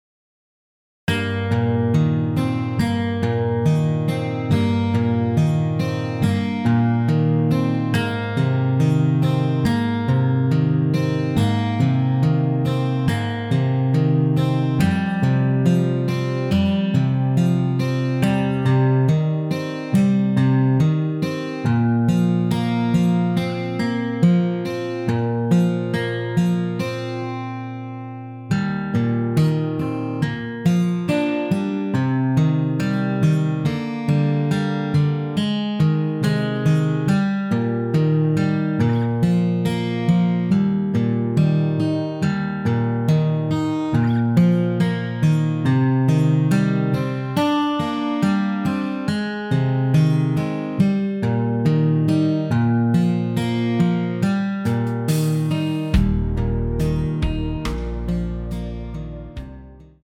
원키에서 +8올린 MR입니다.
Eb
앞부분30초, 뒷부분30초씩 편집해서 올려 드리고 있습니다.
중간에 음이 끈어지고 다시 나오는 이유는